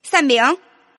Index of /client/common_mahjong_tianjin/mahjongwuqing/update/1162/res/sfx/tianjin/woman/